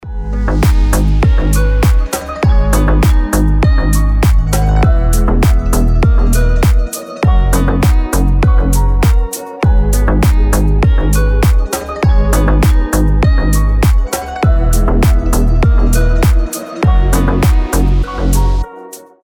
красивые
deep house
мелодичные
без слов
пианино